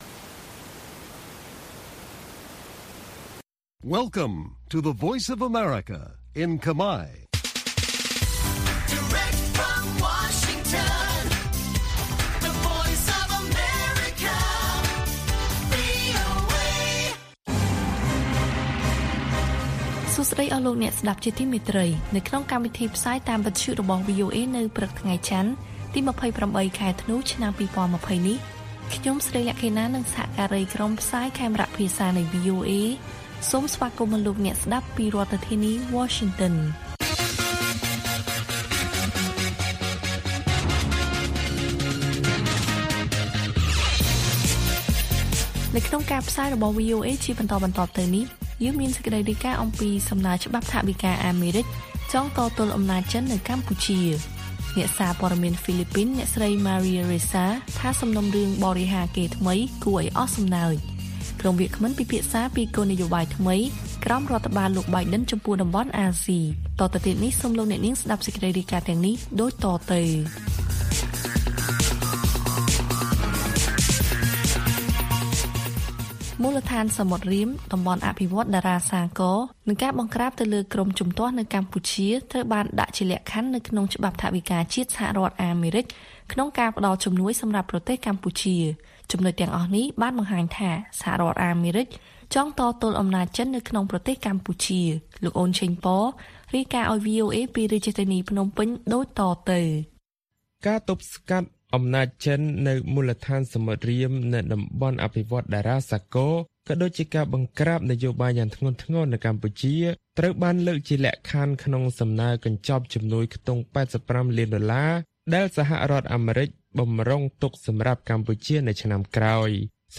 ព័ត៌មានពេលព្រឹក៖ ២៨ ធ្នូ ២០២០